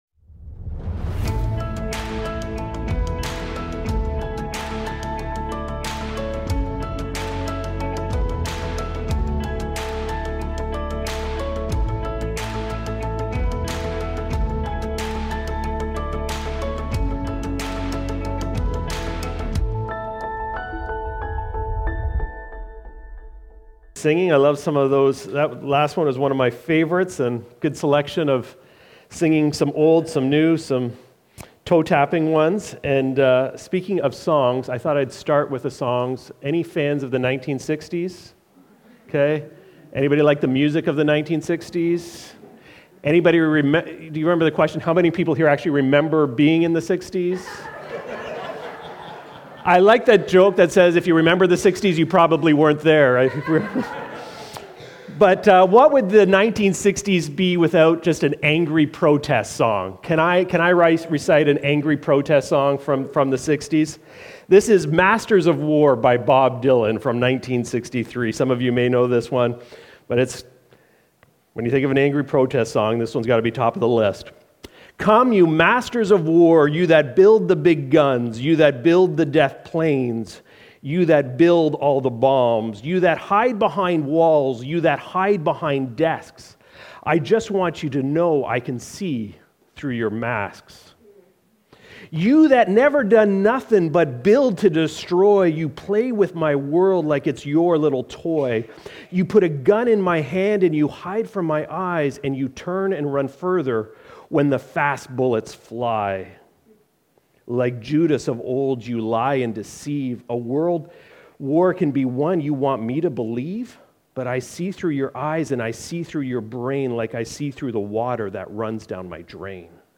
Recorded Sunday, June 8, 2025, at Trentside Bobcaygeon.